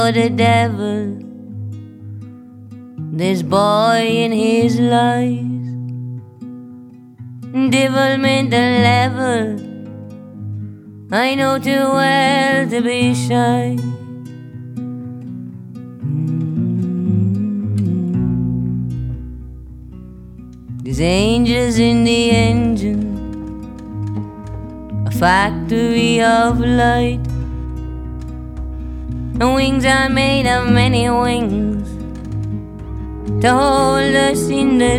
# Contemporary Folk